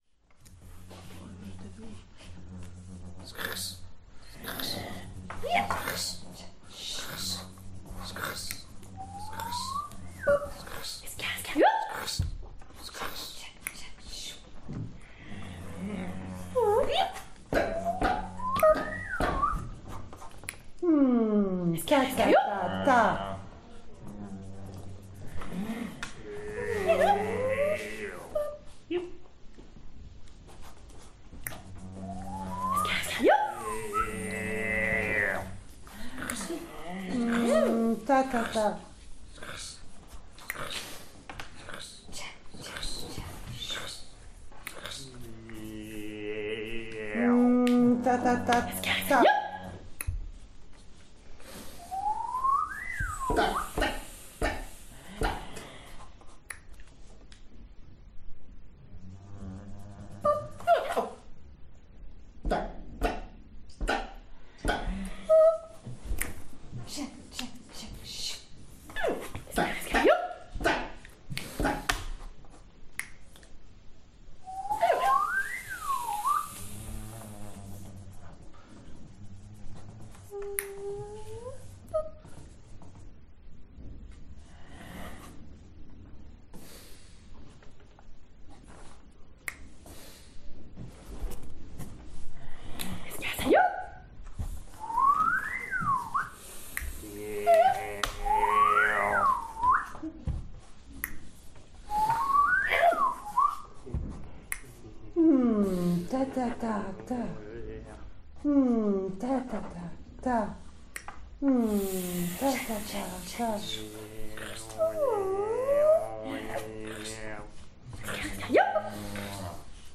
• Une séquence sonore produite avec la voix ou la bouche, l’appareil vocal.
L’atelier se déroule dans une salle de séminaire avec une grande table centrale, des chaises pour s’assoir autour, mais peu d’espace pour circuler ou faire de grands mouvements du corps.
Une improvisation est lancée. On n’a le droit que de produire exactement sa propre signature. Pas celle des autres. L’improvisation concerne seulement le placement dans le temps de sa signature.
Improvisation 1. Durée : 2’32” :